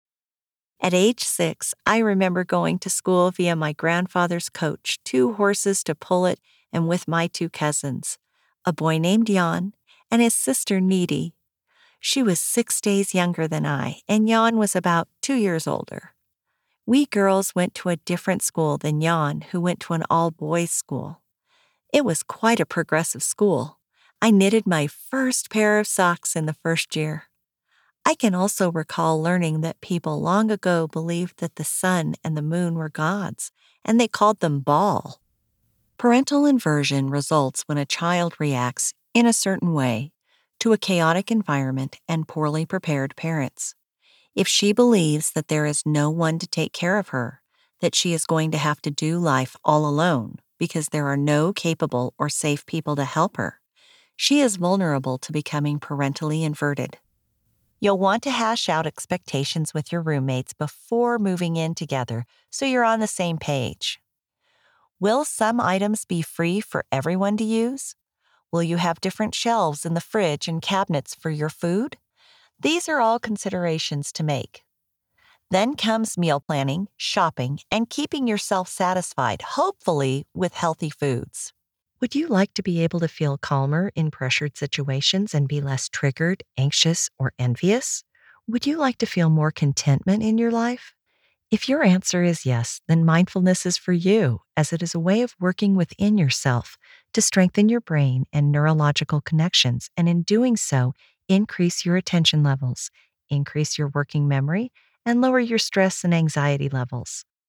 Audiobook Demo
Audiobook DEMO.mp3